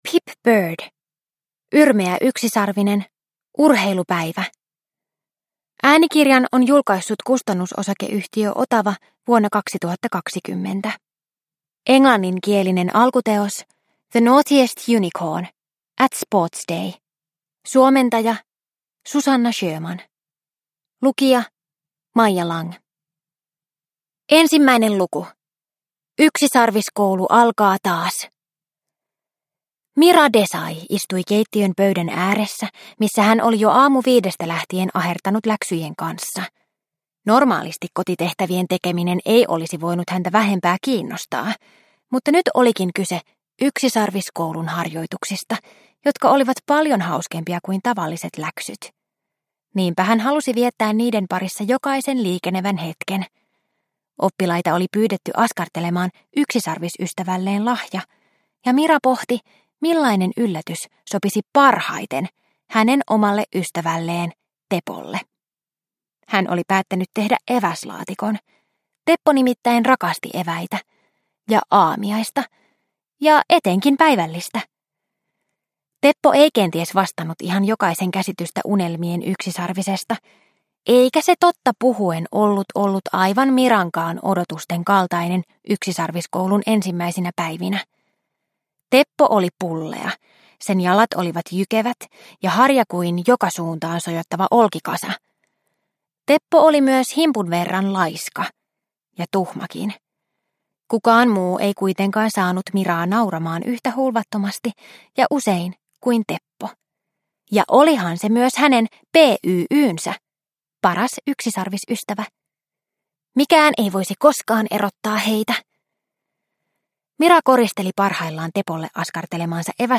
Yrmeä yksisarvinen - Urheilupäivä – Ljudbok